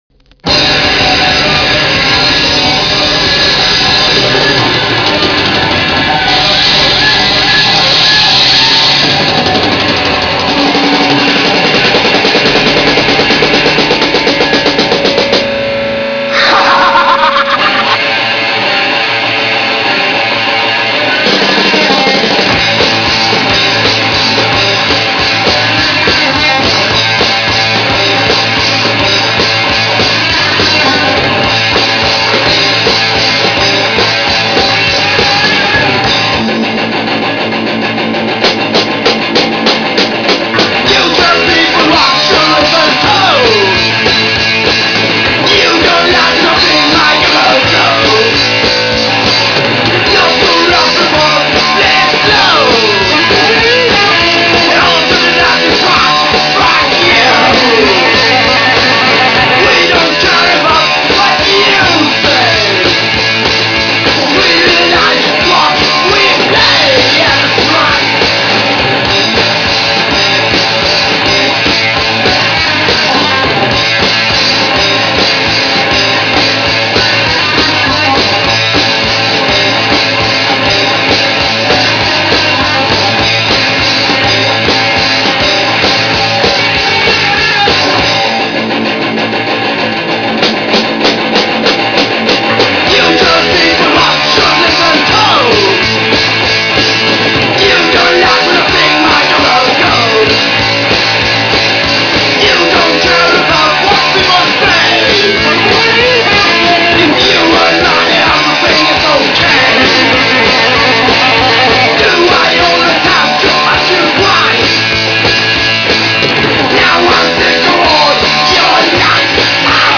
両面ともLoud&Fastな楽曲で